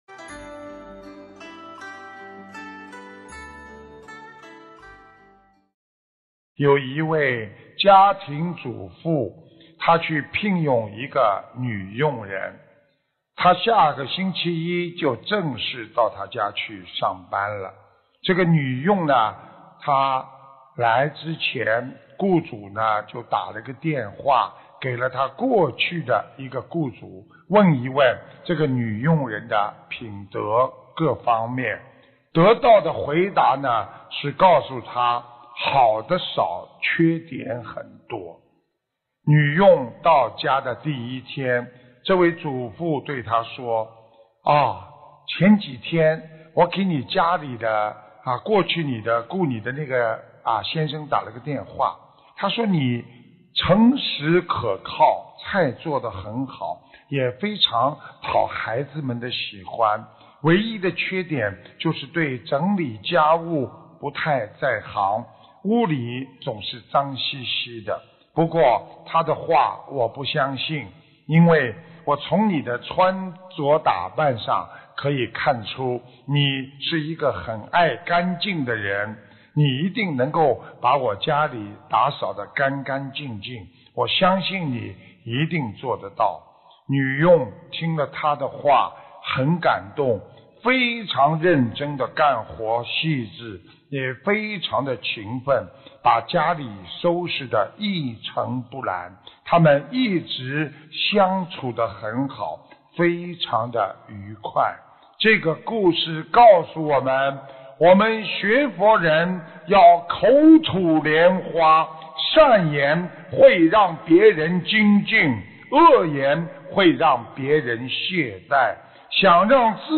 音频：马来西亚 沙巴《玄藝综述》大型解答会 2016年8月13日 节选（三）